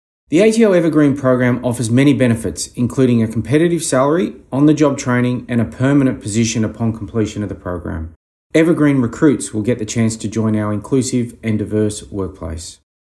The following recordings feature ATO Deputy Commissioner and Indigenous Champion, Matthew Hay, discussing the 2022 Evergreen program.